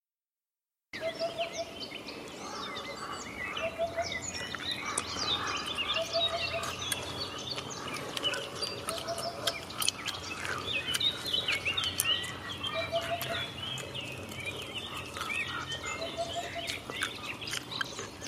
Upupa chiacchierona?
Mi sembrerebbe un'Upupa ma non dovrebbe emettere tre suoni consecutivi invece di quattro?
Per me è un'upupa, anche se emette quattro suoni consecutivi.
Mi ero dimenticato di scriverlo ma la registrazione è del 23 Aprile 2021, Lago di Cornino (UD).